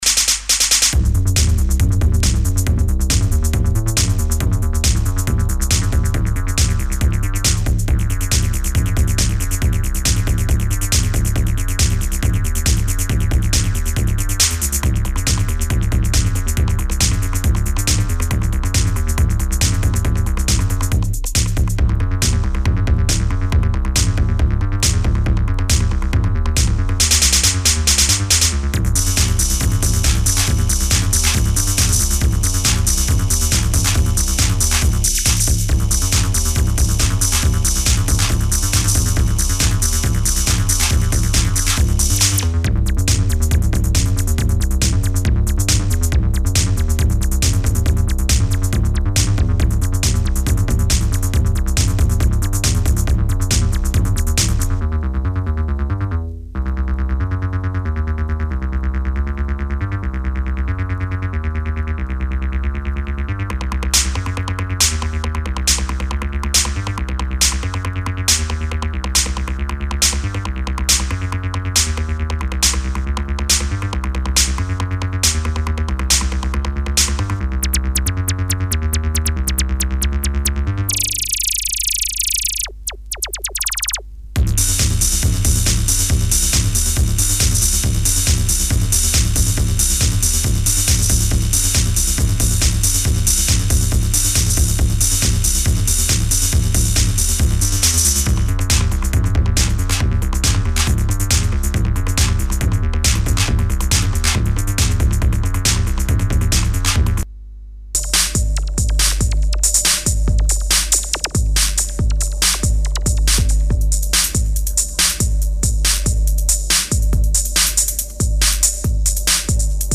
Techno-Electro